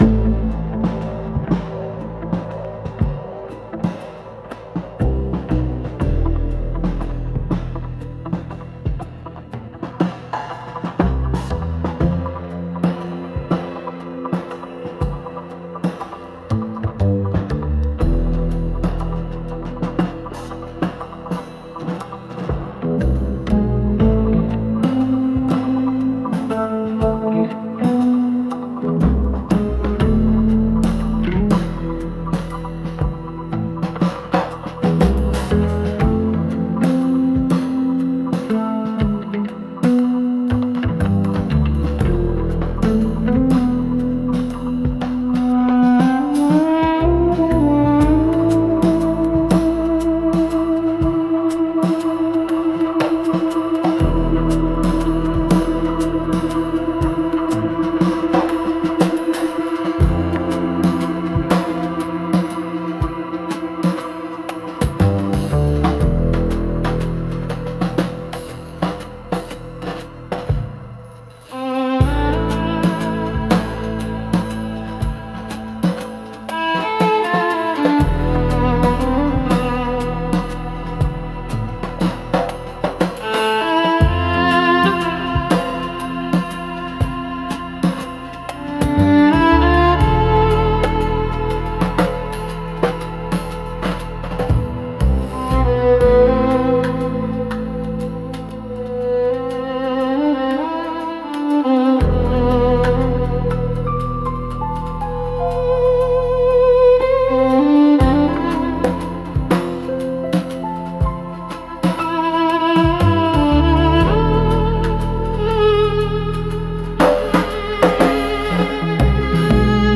дудук_трип
duduk_trip.mp3